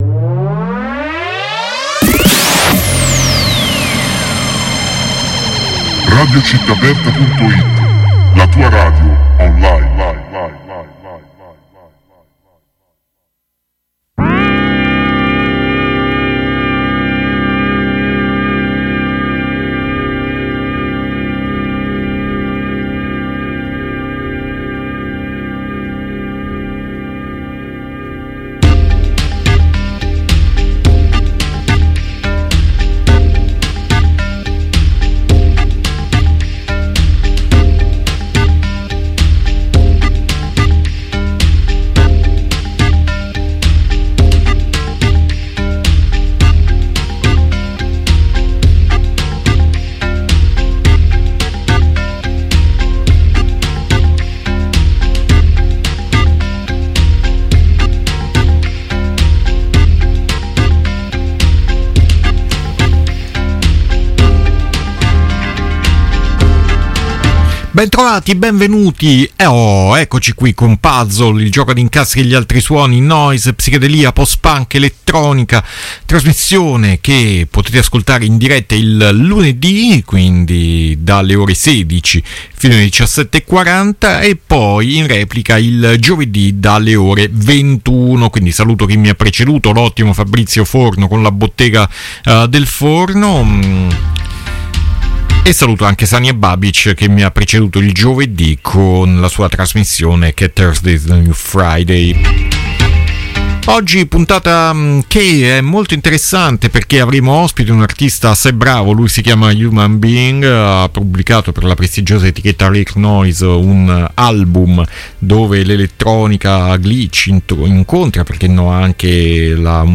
Intervista humanbeing